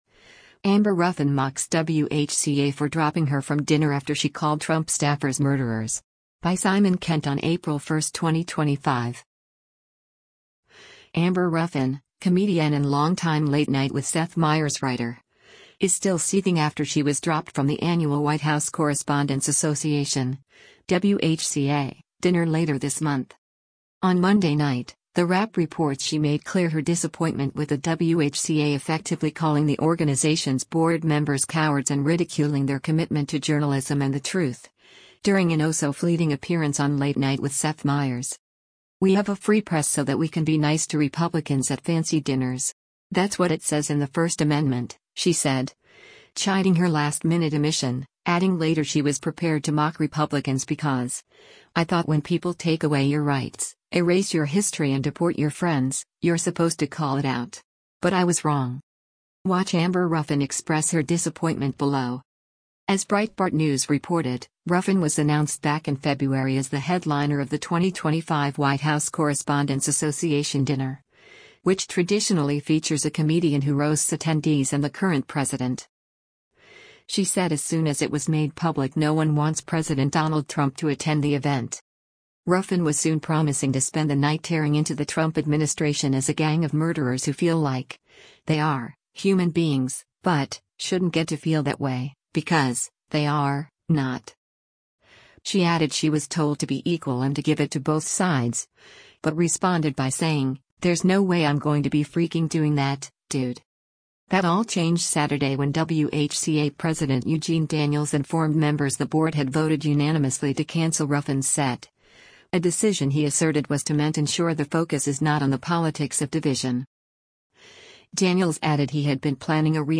On Monday night, The Wrap reports she made clear her disappointment with the WHCA effectively calling the organization’s board members cowards and ridiculing their commitment to journalism and the truth, during an oh-so-fleeting appearance on “Late Night With Seth Meyers.”
Watch Amber Ruffin express her disappointment below: